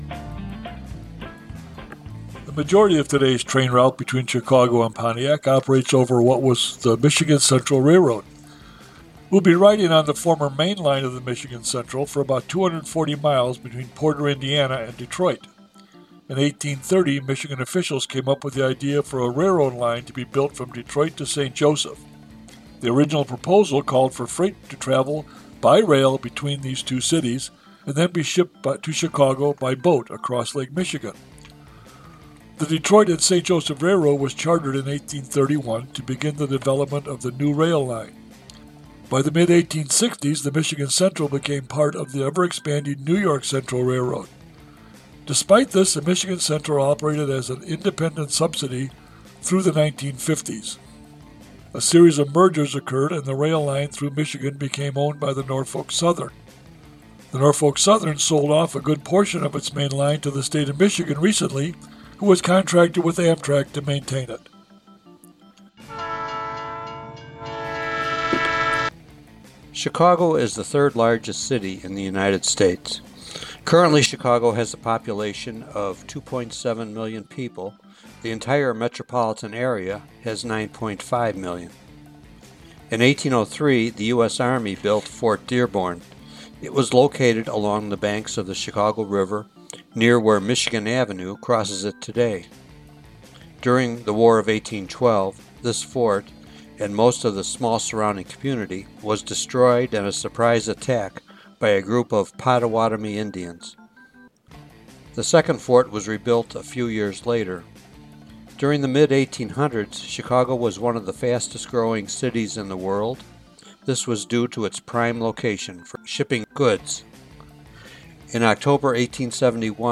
Simply purchase an easy-to-use MP3 podcast and you will have a Rail Rangers Guide sharing fun stories about the towns you will be traveling through.